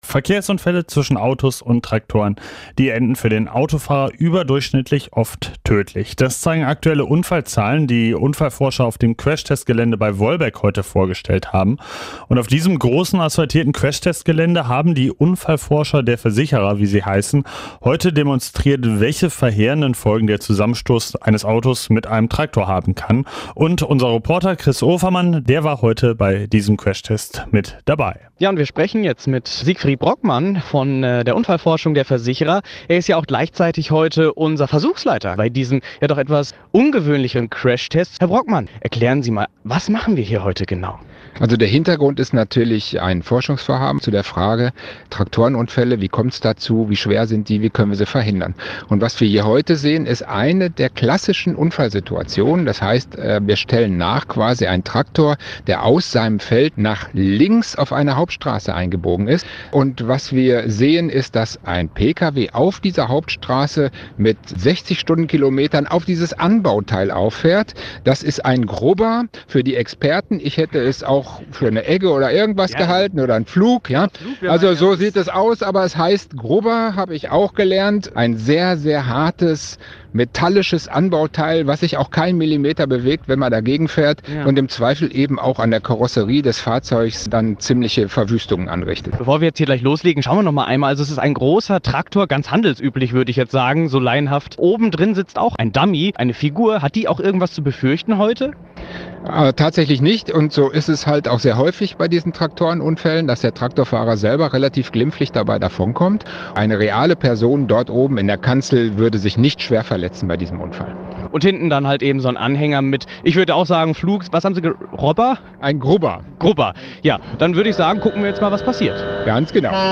Reportage: Der Traktor-Crashtest in Wolbeck